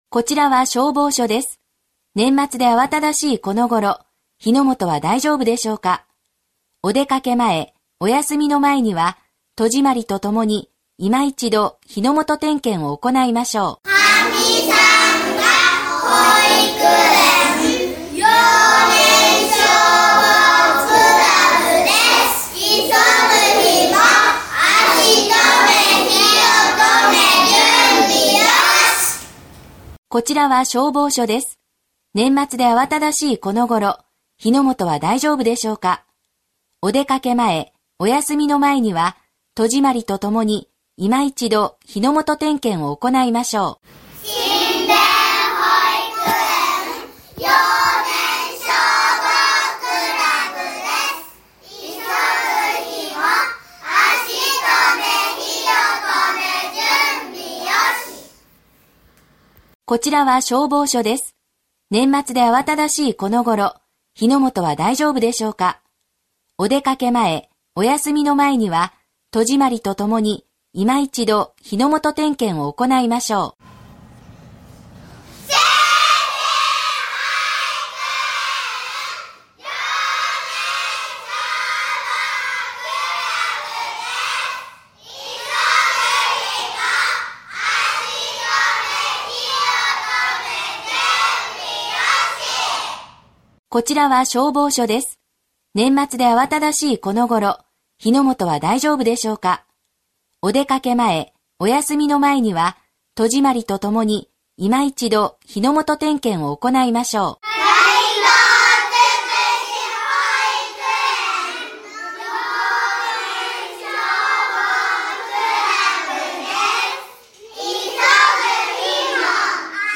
幼年消防クラブ員のかわいい元気いっぱいの声で録音した全国統一防火標語「急ぐ日も　足止め火を止め　準備よし」を使用し、消防車で巡回広報を行います。
歳末特別警戒に係る幼年消防クラブ員による消防車両巡回広報の音声